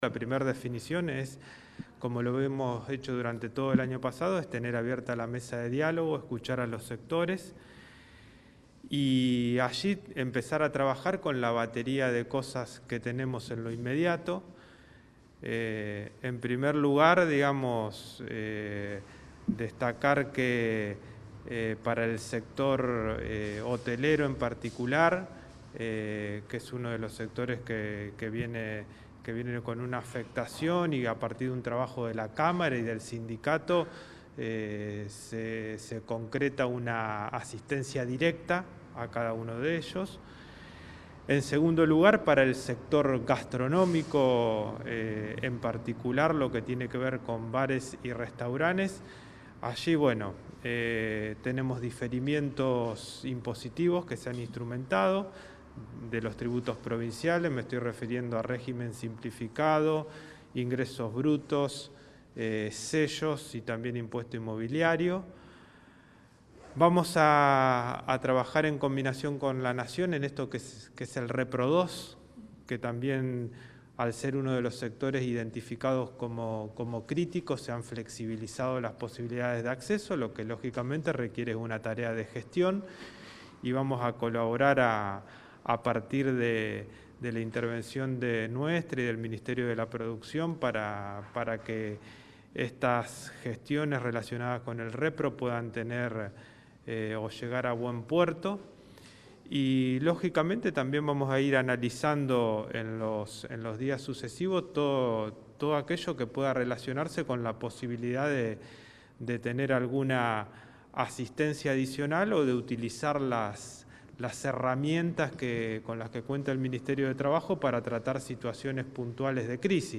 Juan Manuel Pusineri - Ministro de Trabajo, Empleo y Seguridad Social.